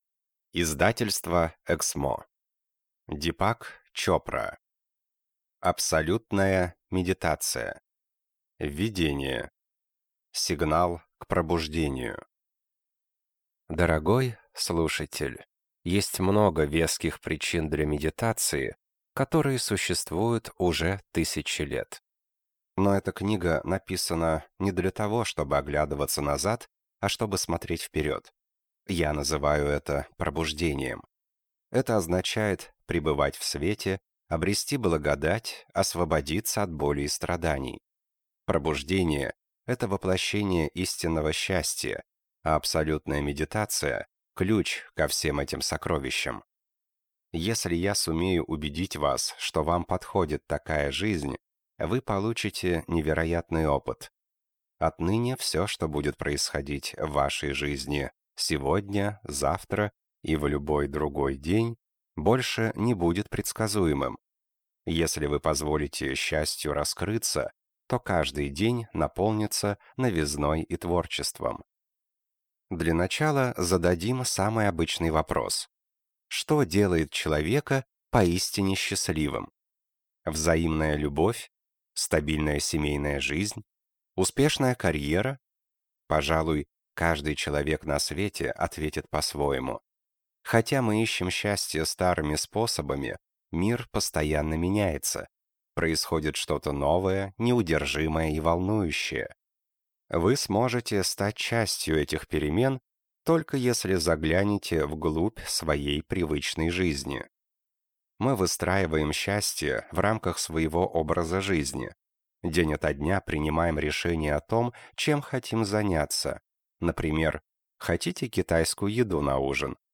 Аудиокнига Абсолютная медитация. Путь к осознанной и полной жизни | Библиотека аудиокниг